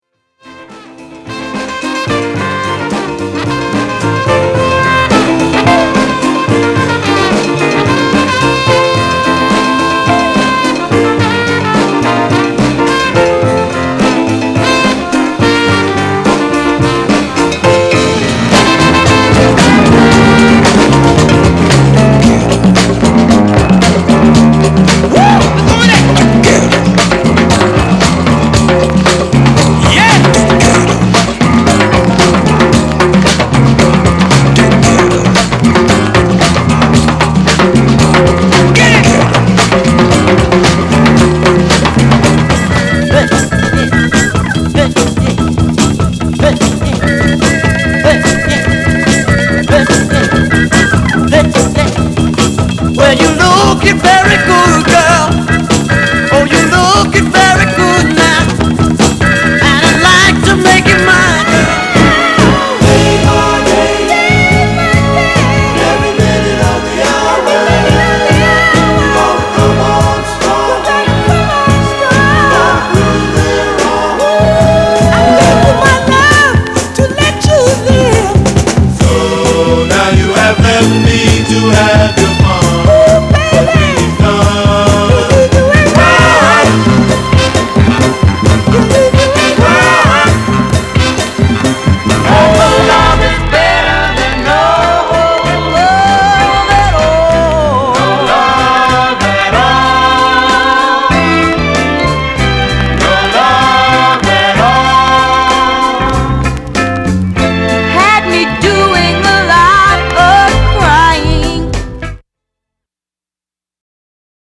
category Fusion & New Age